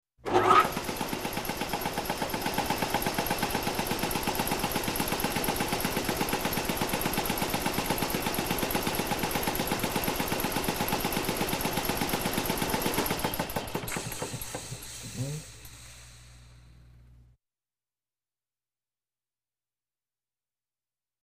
Compressor
Motorized Shop Tool, Compressor Motor 3; Turn On, Steady Chugging Motor, And Off With Chugs And Air Release.